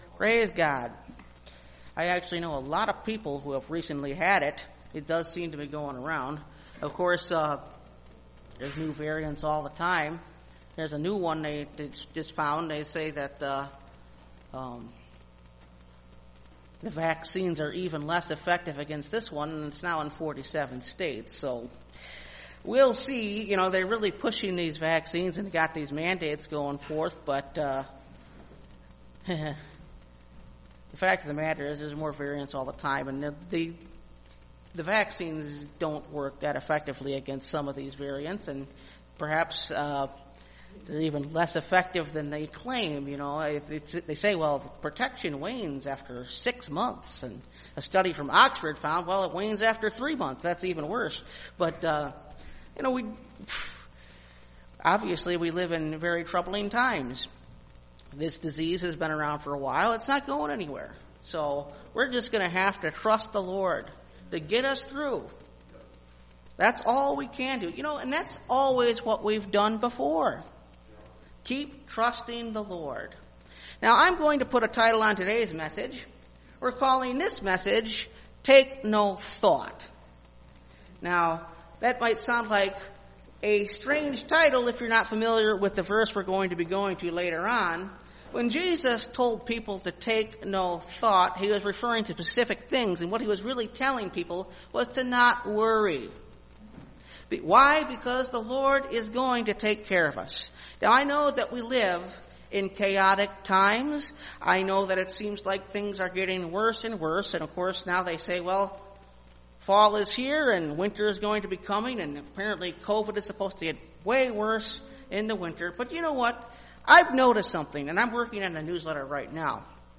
Take No Thought (Message Audio) – Last Trumpet Ministries – Truth Tabernacle – Sermon Library